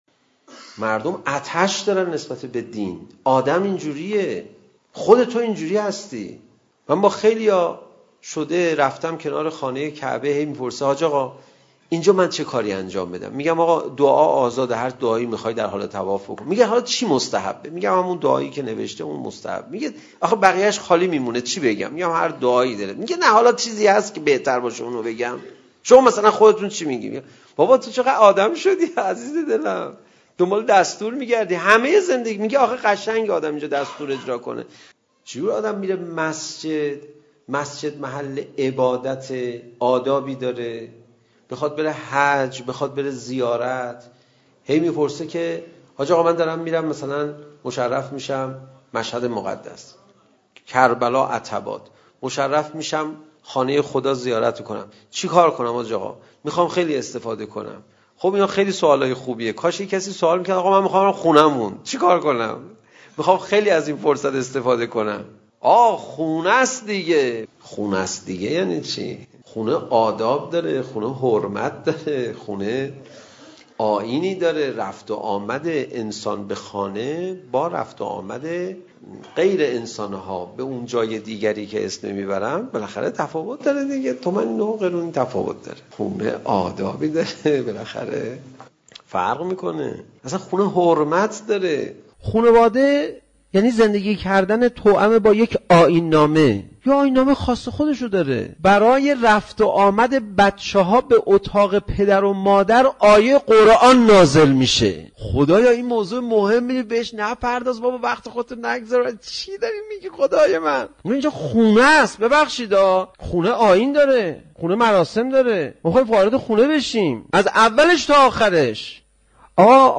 • حاصل خلاصه برداری صوتی و موضوعی سخنرانی حجت الاسلام پناهیان با عنوان خانواده خوب